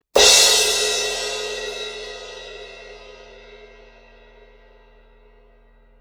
Loud Hit